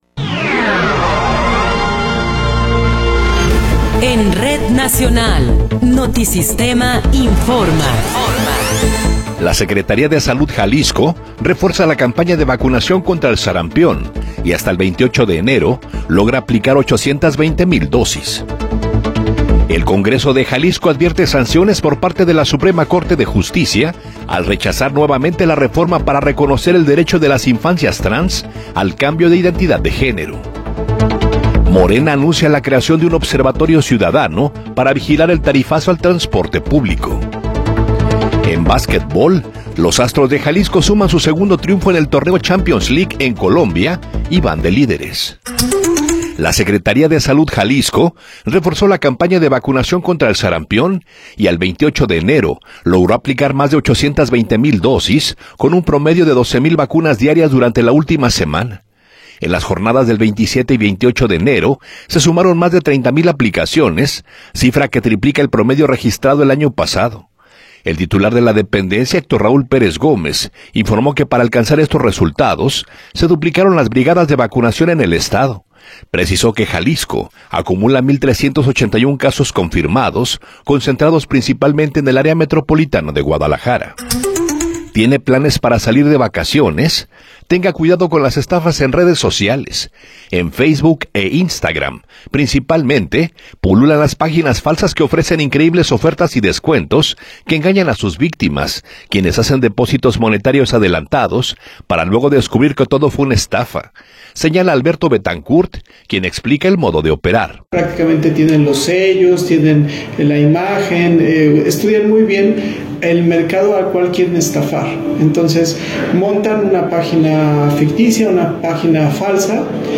Noticiero 9 hrs. – 30 de Enero de 2026
Resumen informativo Notisistema, la mejor y más completa información cada hora en la hora.